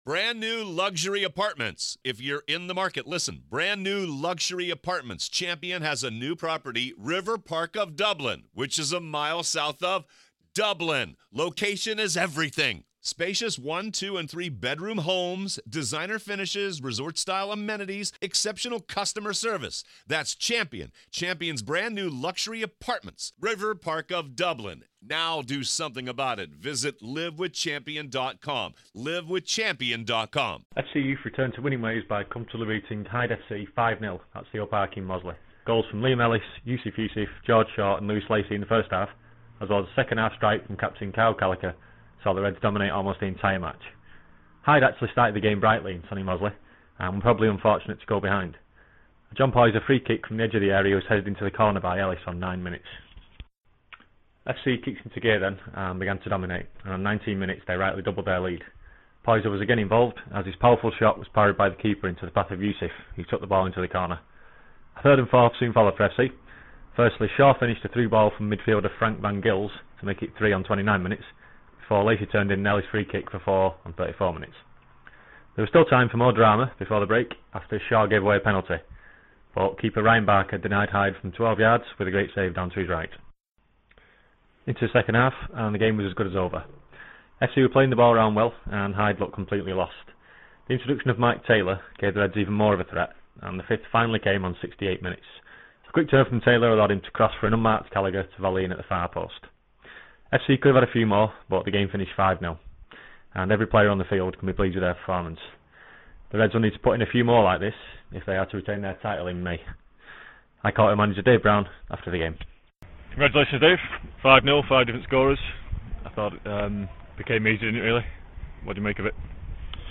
Youth Match Report - Hyde FC (a)